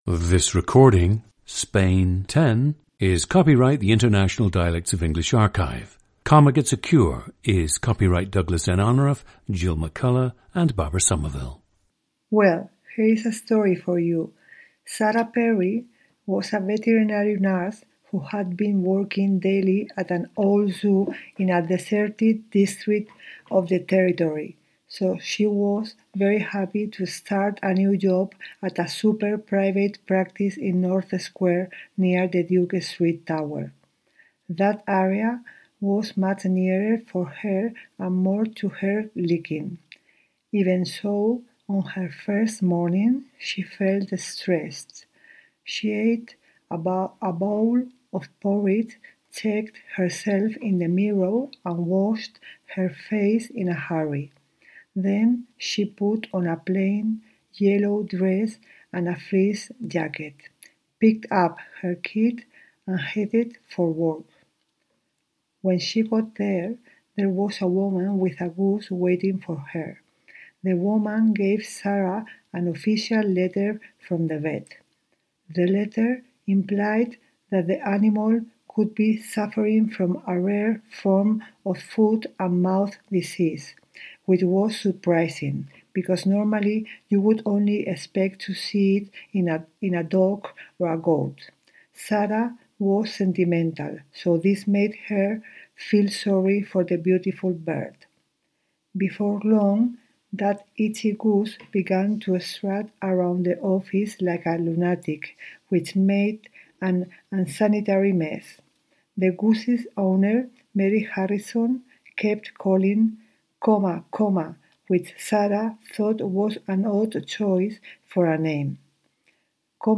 GENDER: female
Her parents and other members of her family have strong Andalusian accents.
However, she says she does not have the typical Andalusian dialect when speaking Spanish, resulting in some people perceiving her dialect as a “neutral” Spanish sound.
• Recordings of accent/dialect speakers from the region you select.
The recordings average four minutes in length and feature both the reading of one of two standard passages, and some unscripted speech.